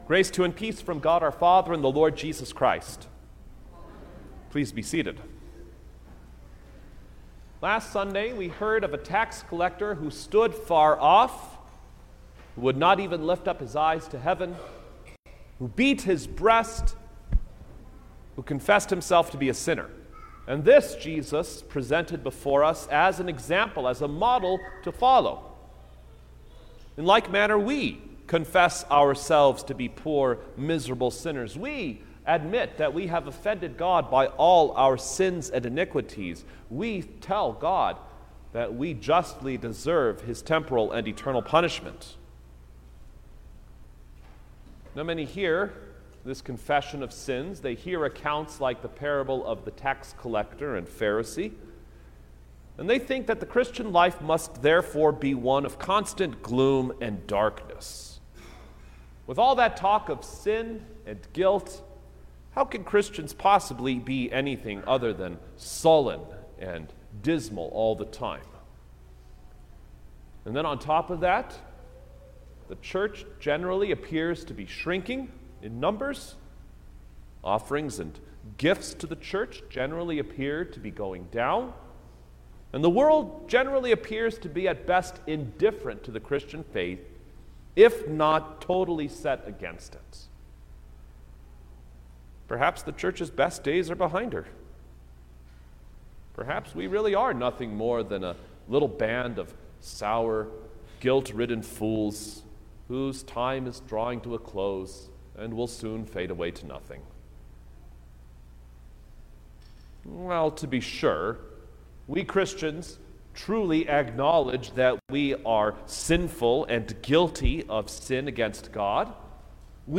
September-4_2022_Twelfth-Sunday-after-Trinity_Sermon-Stereo.mp3